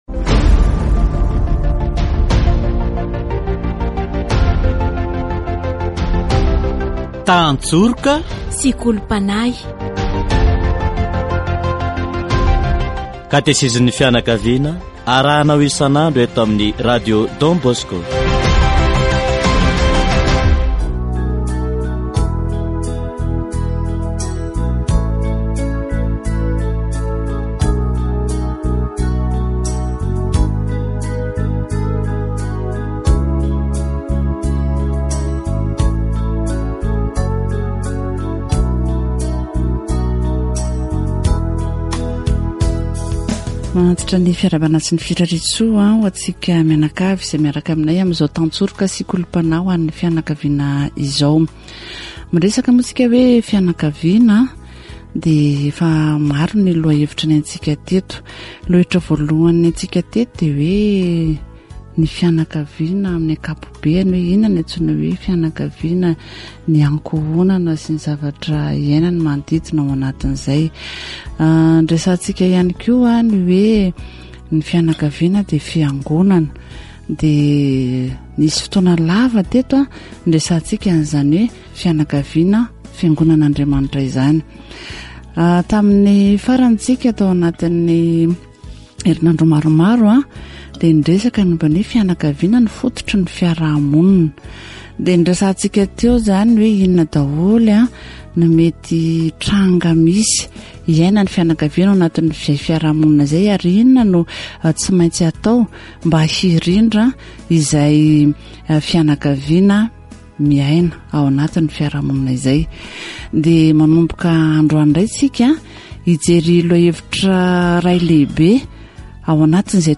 Catechesis on the environment